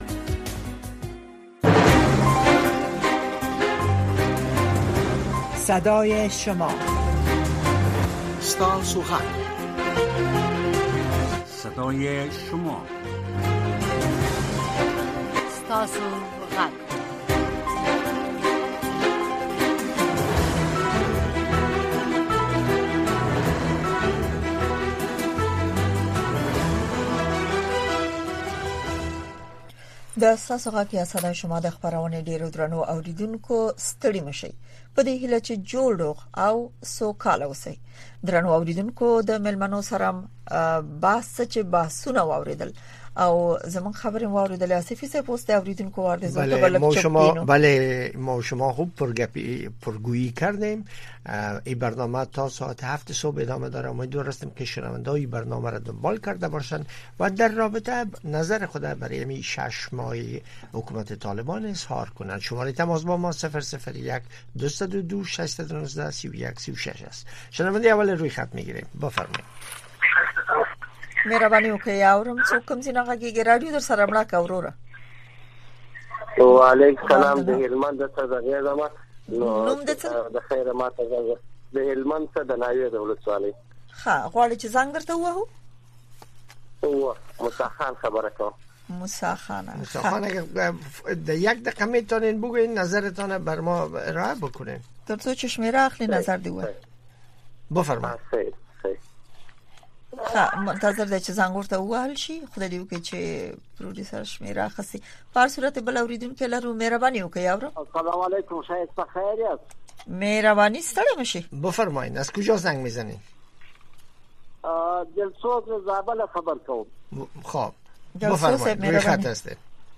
در برنامۀ صدای شما شنوندگان رادیو آشنا صدای امریکا به گونۀ مستقیم با ما به تماس شده و نظریات، نگرانی‌ها، دیدگاه، انتقادات و شکایات شان را با گردانندگان و شنوندگان این برنامه در میان می‌گذارند. این برنامه به گونۀ زنده از ساعت ۱۰:۰۰ تا ۱۰:۳۰ شب به وقت افغانستان نشر می‌شود.